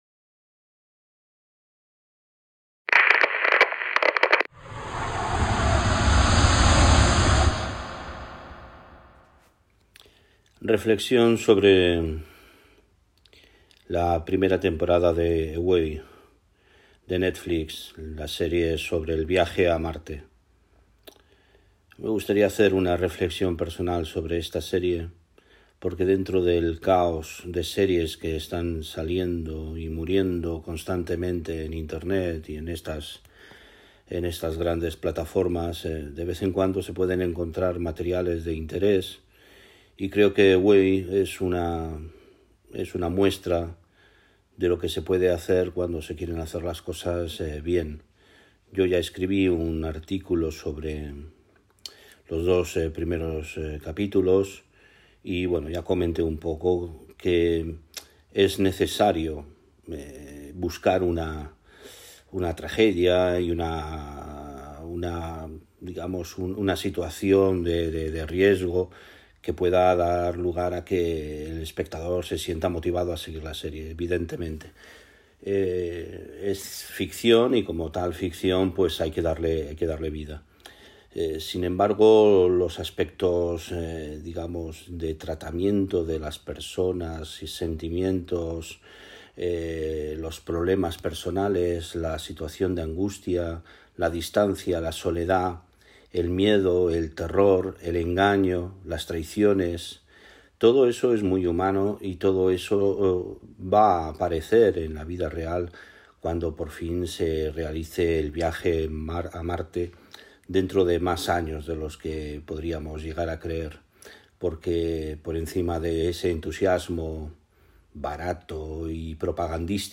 No es un «podcast» realmente, simplemente es una grabación hecha tras ver el capítulo diez. Y en esta grabación exploro los aspectos que me han parecido más interesantes de esta serie.